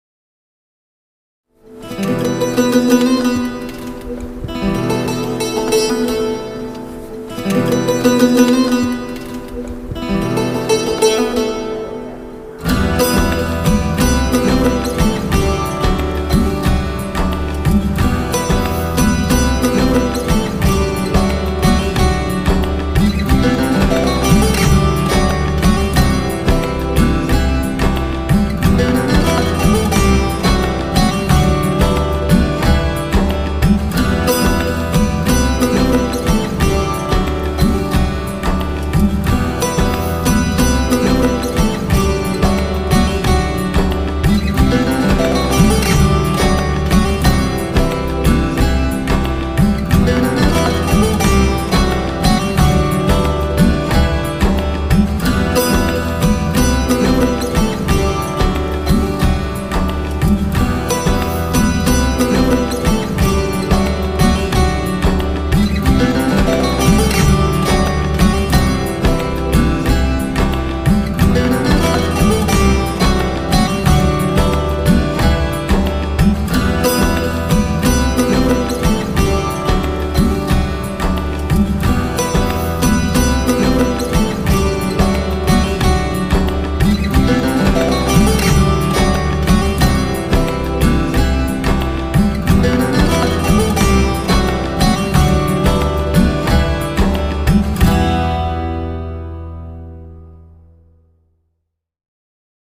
tema dizi müziği, mutlu huzurlu eğlenceli fon müzik.